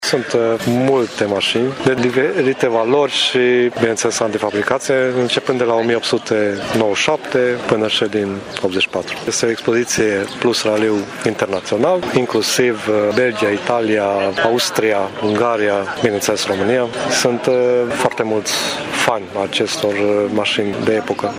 Cel mai vechi exemplar expus este fabricat în anii 1800, spune unul dintre organizatori